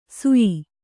♪ suyi